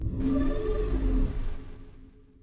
portal_enter_001.wav